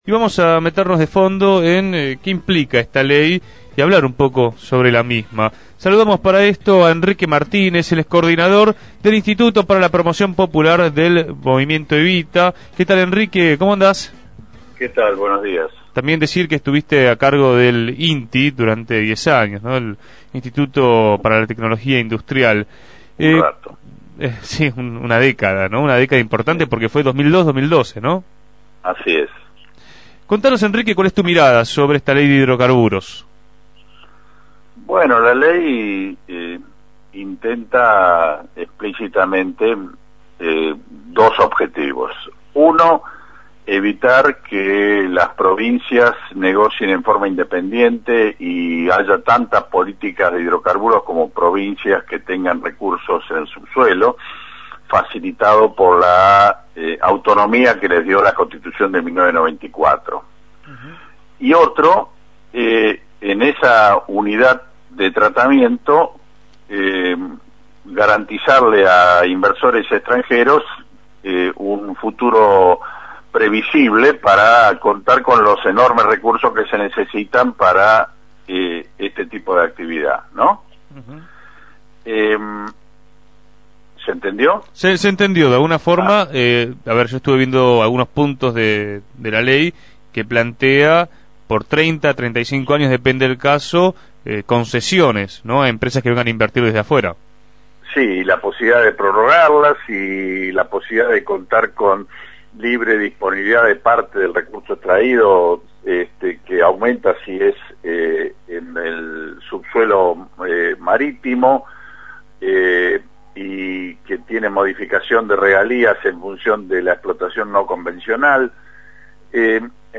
Enrique Martínez, coordinador del Instituto para la Producción Popular dentro del Movimiento Evita y ex Presidente del Instituto Nacional de Tecnología Industrial (INTI), analizó en Punto de Partida la flamante Ley de Hidrocarburos sancionada por el Congreso Nacional.
En el aire de Radio Gráfica, reflejó que esta iniciativa tiene dos objetivos.